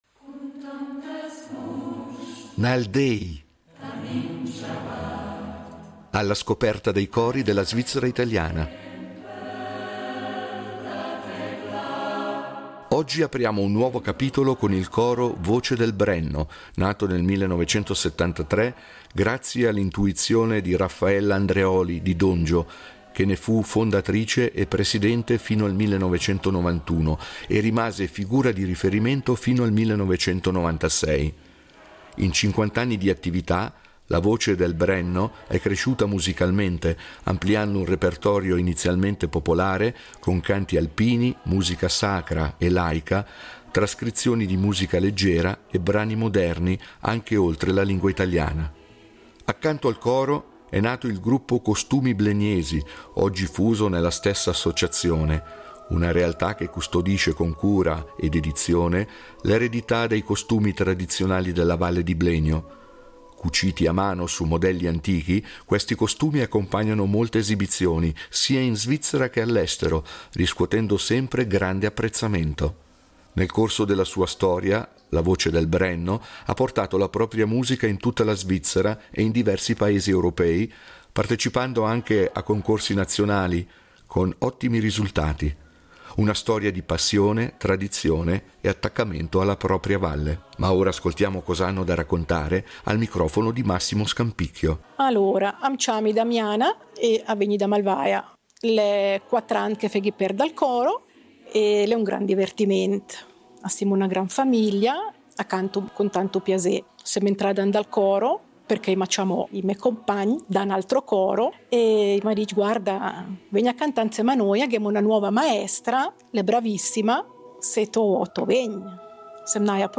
Nal déi, cori della svizzera italiana